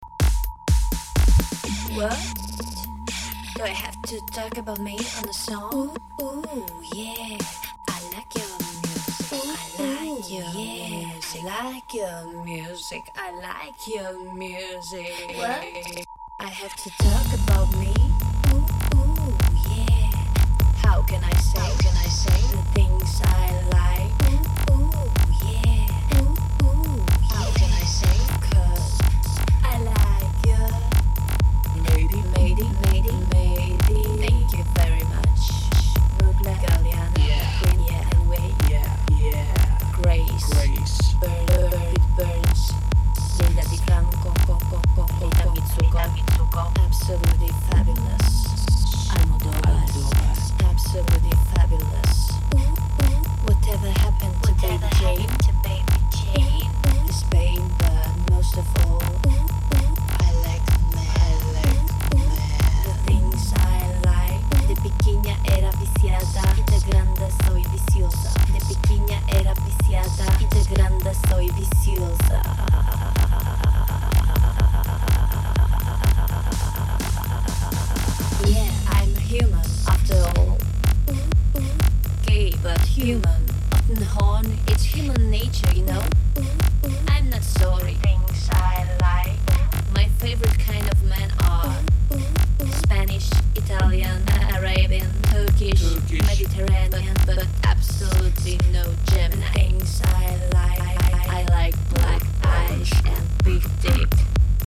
With the crispy voices
pure acid techno 12” sensuality